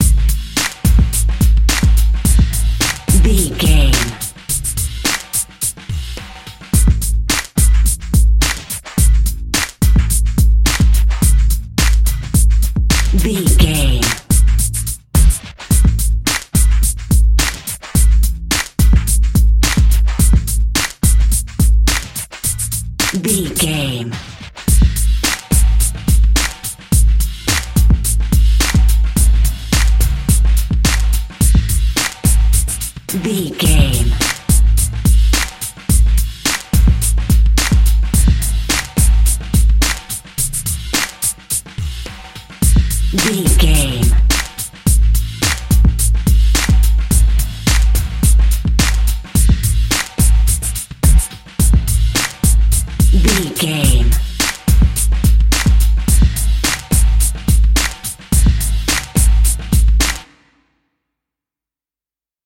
Aeolian/Minor
drum machine
synthesiser
drums
hip hop
Funk
neo soul
acid jazz
energetic
bouncy
funky
hard hitting